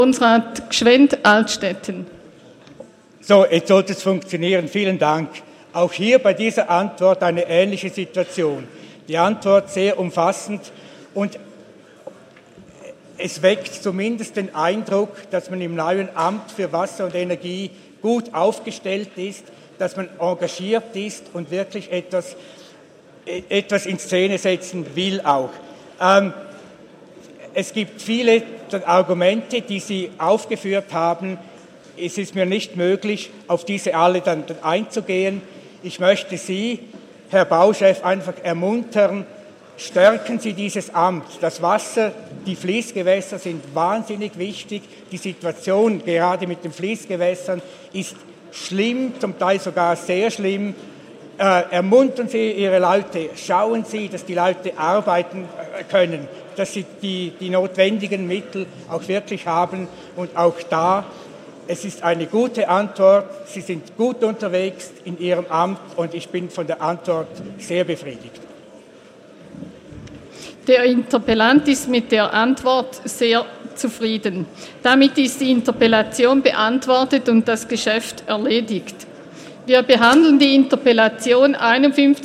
19.2.2018Wortmeldung
Session des Kantonsrates vom 19. und 20. Februar 2018